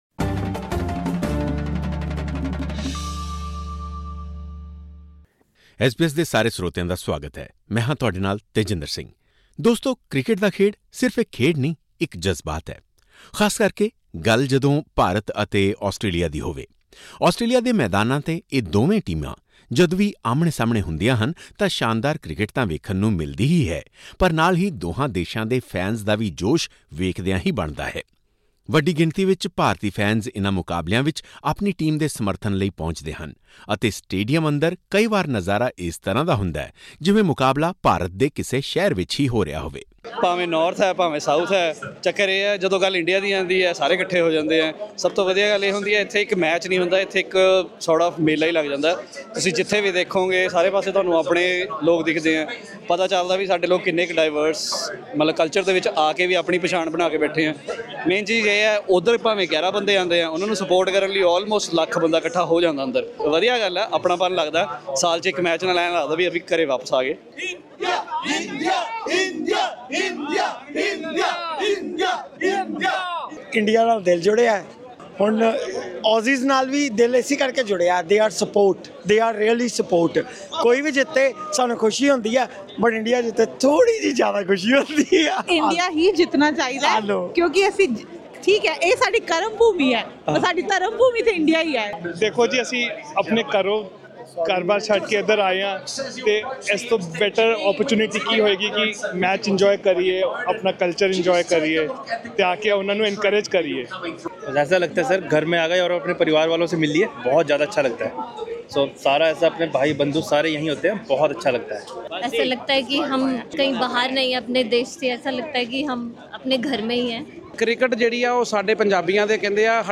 ਇਸ ਨਾਲ ਸਬੰਧਿਤ ਇੱਕ ਰਿਪੋਰਟ ਇਸ ਪੌਡਕਾਸਟ ਰਾਹੀਂ ਸੁਣੋ।